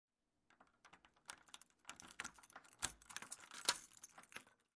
locking safety door
描述：locking a safety door
标签： iekdelta door household home
声道立体声